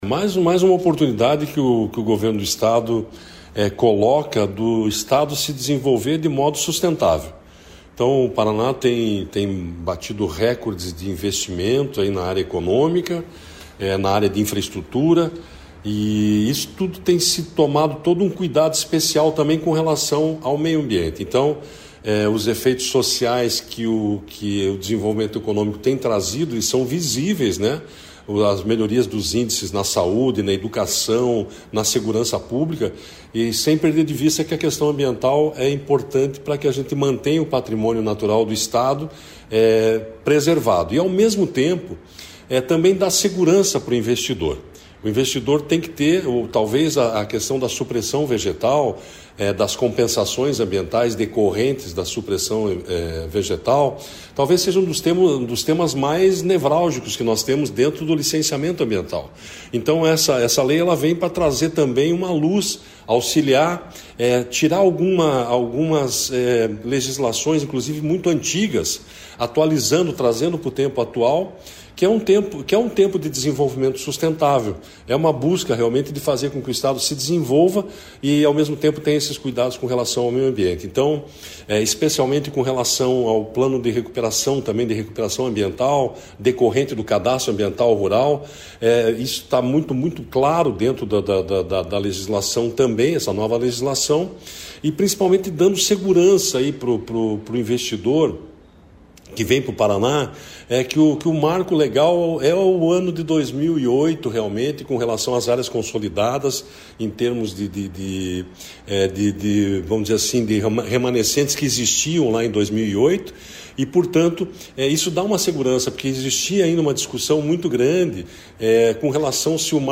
Sonora do diretor-presidente do IAT, Everton Souza, sobre a atualização da legislação florestal enviada para a Alep